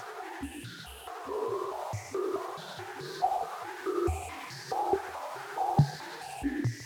STK_MovingNoiseD-140_03.wav